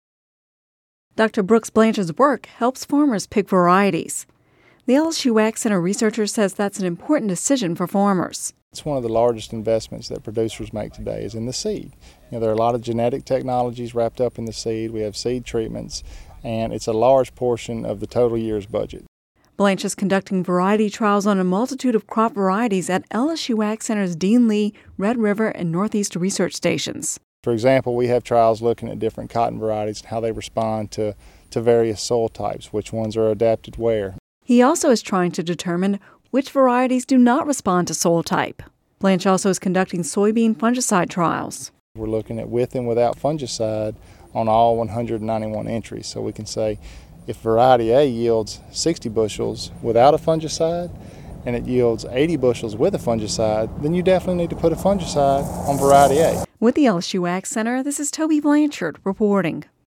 Radio News 08/16/10